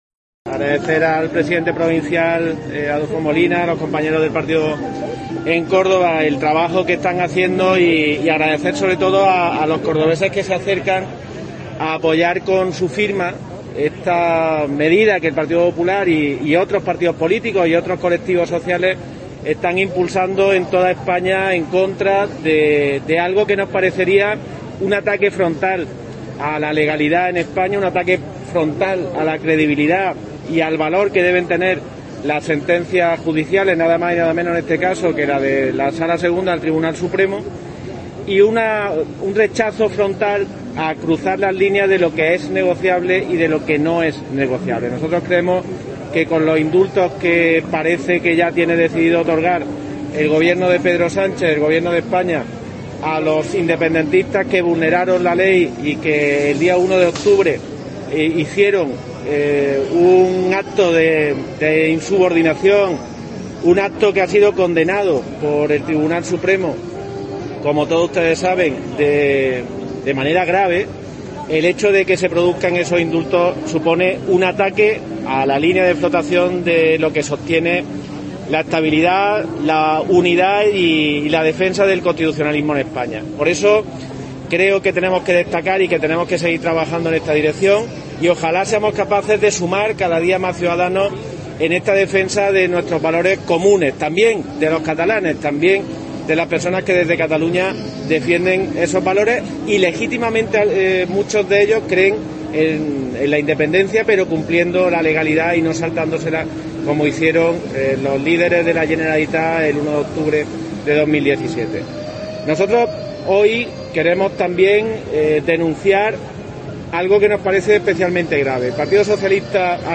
El portavoz del PP en el Parlamento ha señalado que el PSOE-A "no tiene posibilidad de pensar, ni decidir por sí mismo"